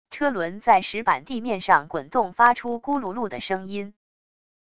车轮在石板地面上滚动发出骨碌碌的声音wav音频